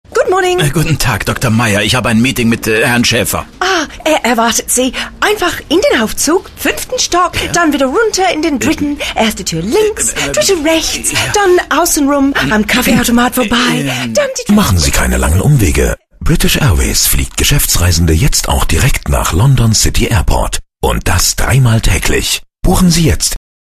englische Synchron-Sprecherin.
Sprechprobe: eLearning (Muttersprache):
english female voice over artist